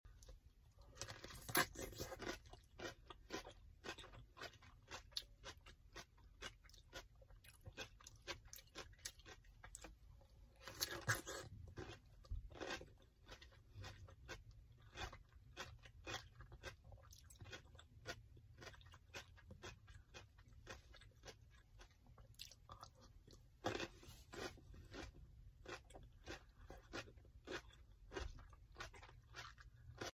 Unapologetically eating a pickle. In sound effects free download